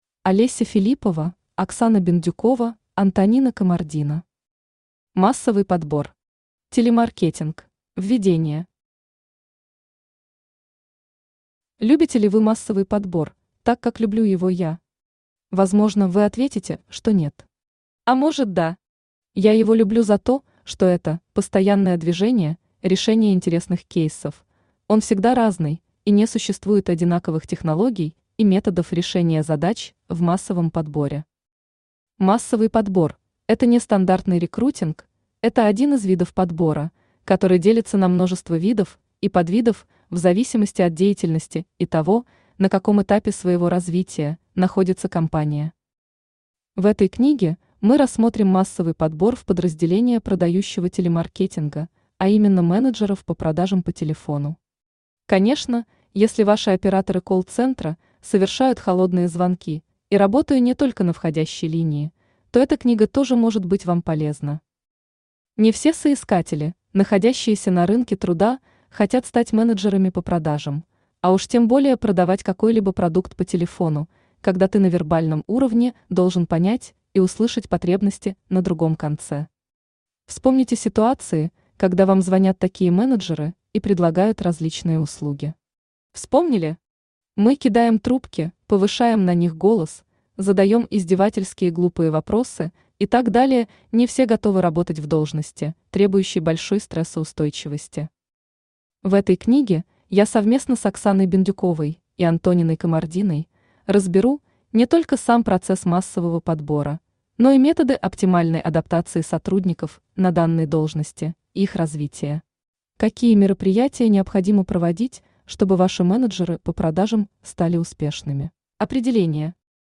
Аудиокнига Массовый подбор. Телемаркетинг | Библиотека аудиокниг
Телемаркетинг Автор Олеся Филиппова Читает аудиокнигу Авточтец ЛитРес.